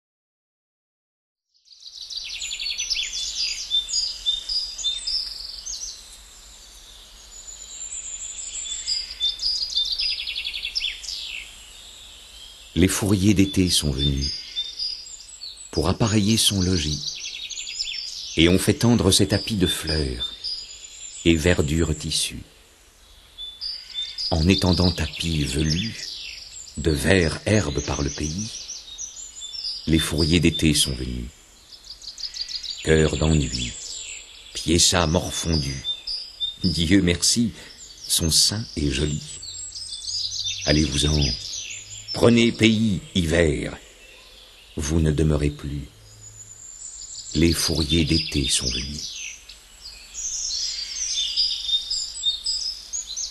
Diffusion distribution ebook et livre audio - Catalogue livres numériques
montage musical (psalmodies d’abbayes, musiques de trouvères, bruitages)